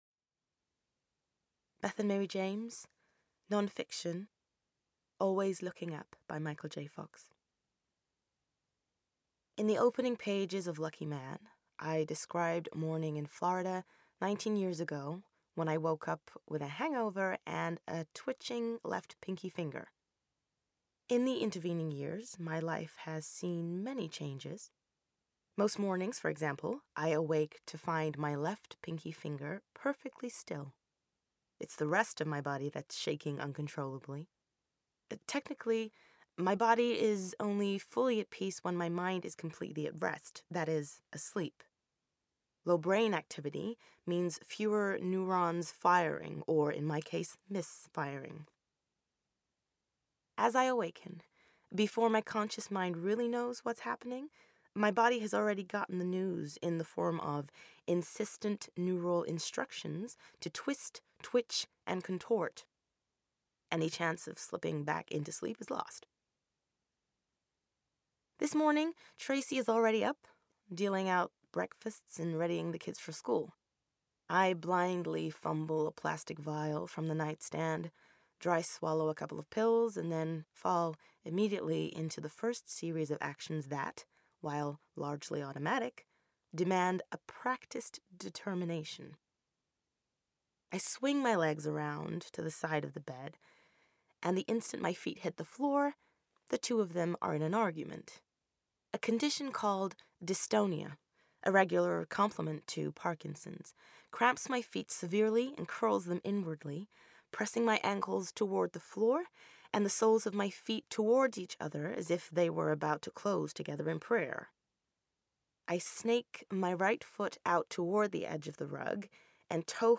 Narrative - US accent